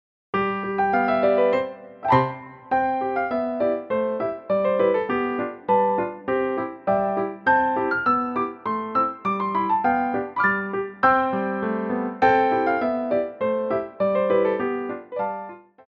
Petit Allegro
2/4 (8x8)